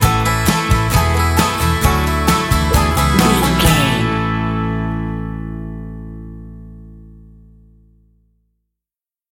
Uplifting
Ionian/Major
Fast
drums
electric guitar
bass guitar
banjo
acoustic guitar
Pop Country
country rock
bluegrass
driving
high energy